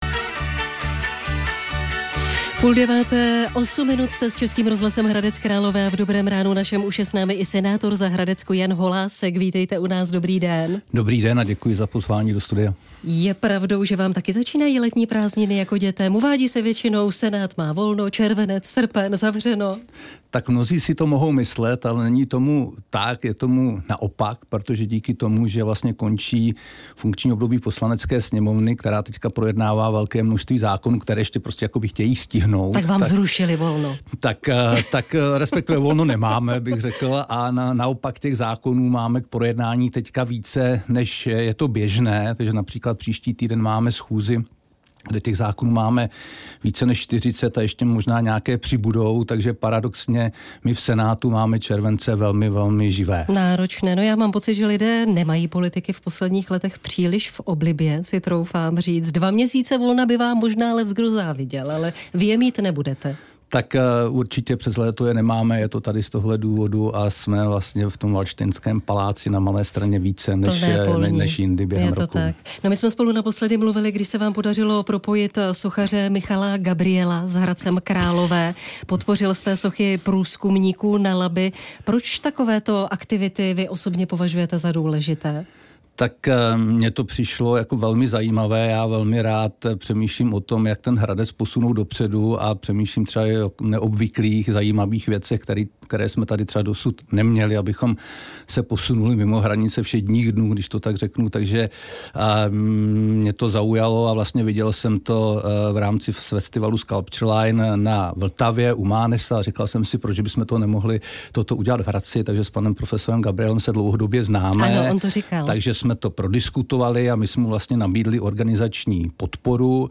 Host ve studiu: Senátor Jan Holásek: Pošťuchování Hradce a Pardubic se usmívám. Tohle souměstí má obrovský potenciál - 27.06.2025